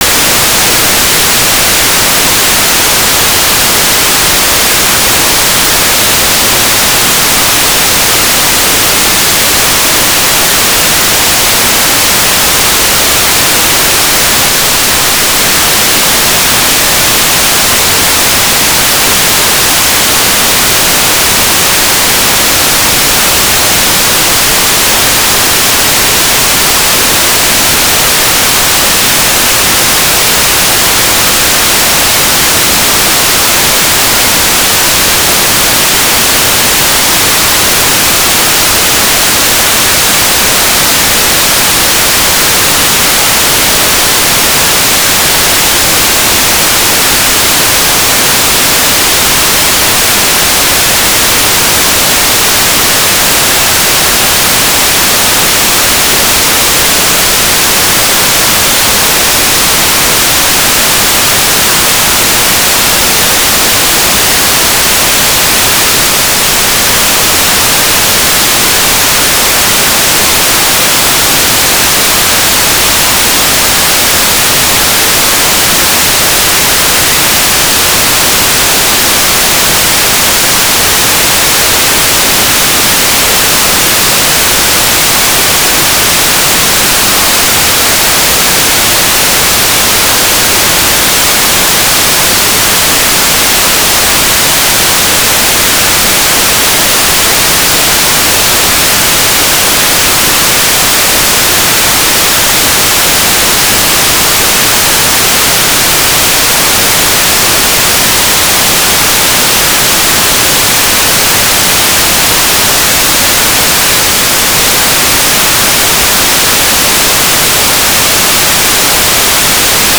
"transmitter_description": "Mode V/V - APRS digipeater",